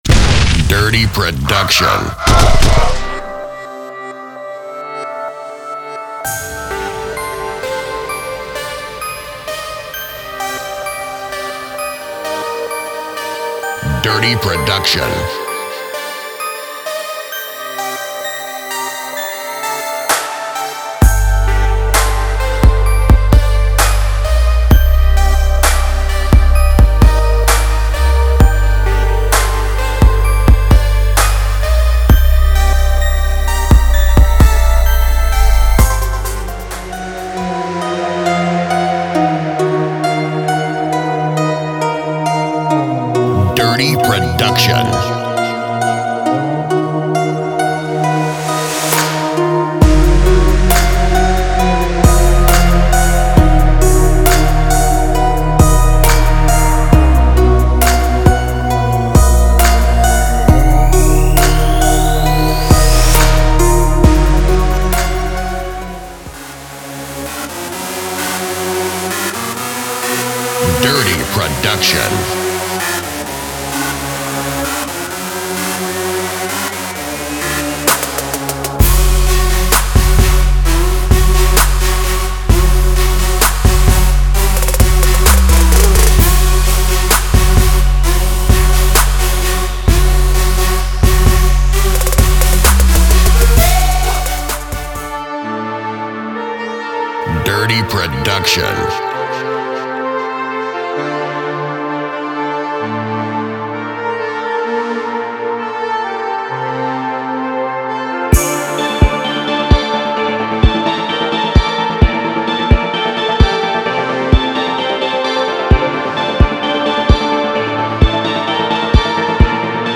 在此包中，您将找到最令人震惊的Chill Trap声音和旋律。
所有套件都装有最新鲜，最独特的声音，包括完美失真的808和各种声音设计技术，以使这款产品脱颖而出。
•完全混合的曲目